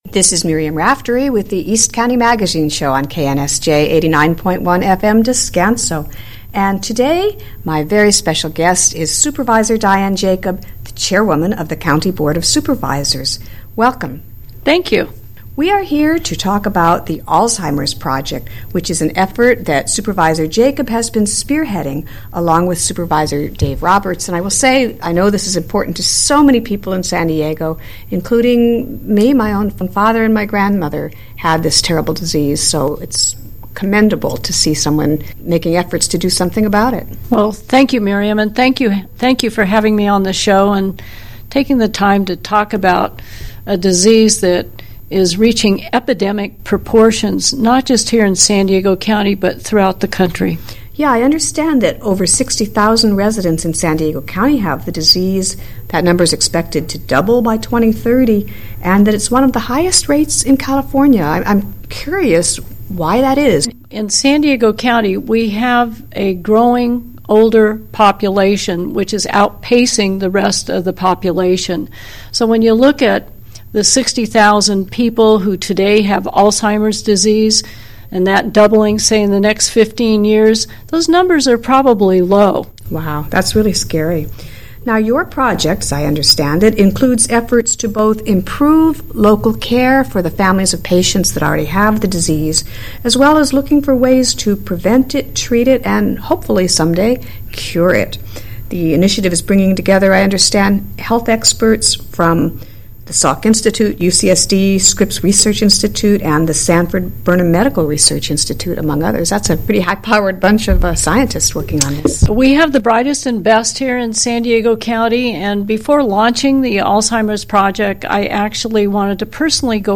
Radio Show Listen to Podcasts Health/Fitness June 11, 2014 (San Diego) – Last week, our interview with Supervisor Dianne Jacob aired on KNSJ radio.